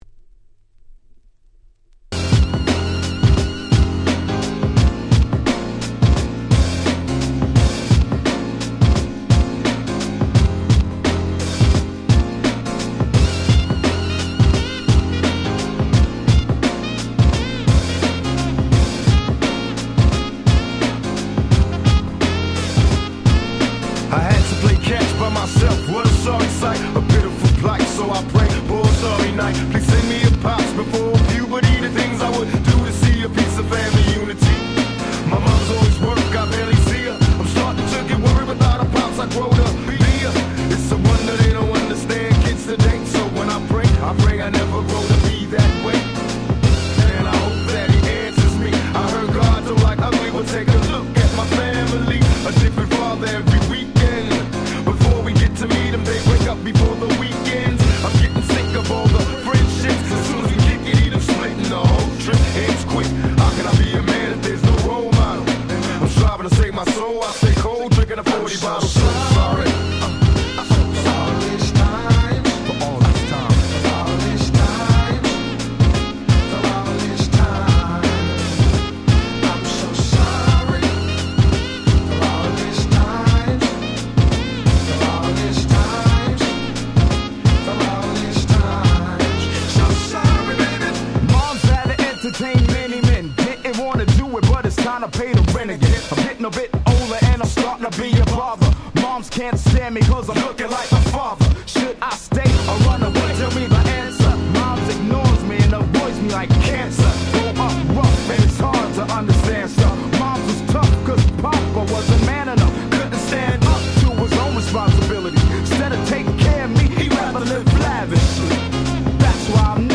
94' Smash Hit Hip Hop !!
Jazzyなネタ使いが格好良いハードボイルドな1曲です！
90's G-Rap Gangsta Rap